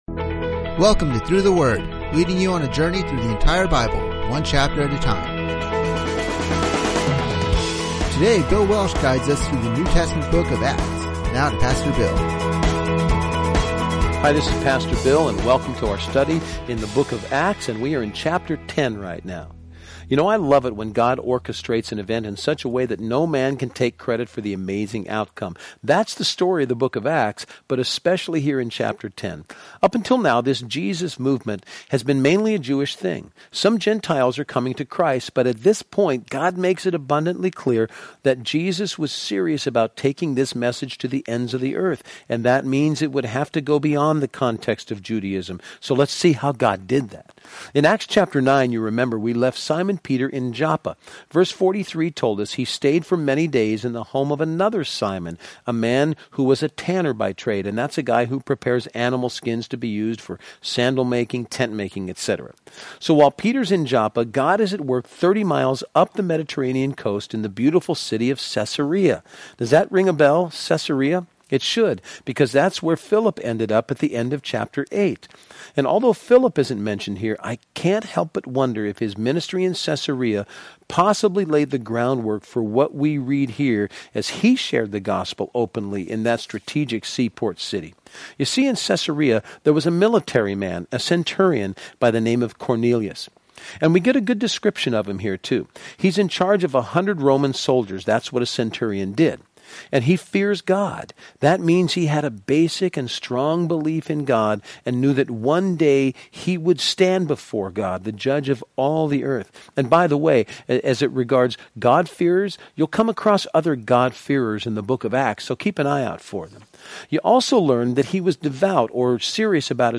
Every chapter brings new insights and understanding as your favorite teachers explain the text and bring the stories to life.